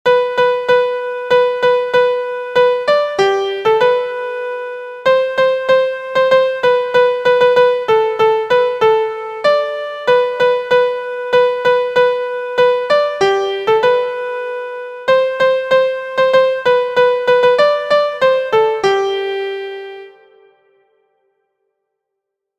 • Key: G Major
• Time: 4/4
• Form: ABAC – chorus